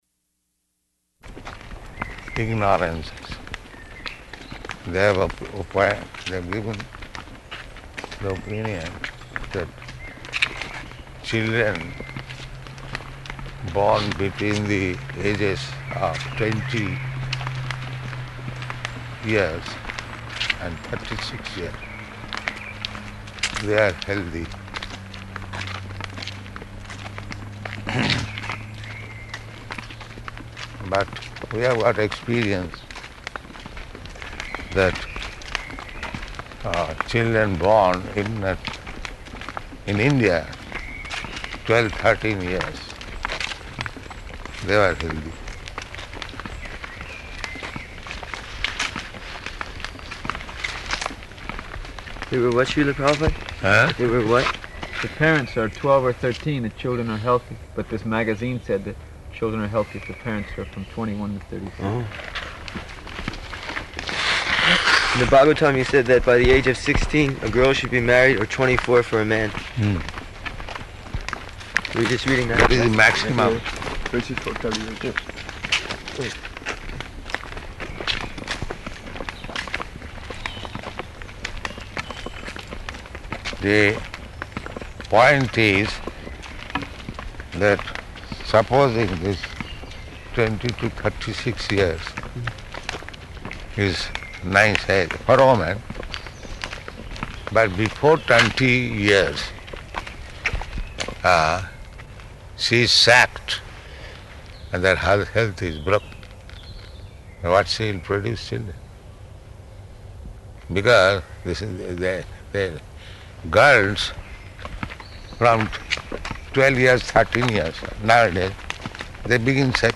Type: Walk
Location: Geneva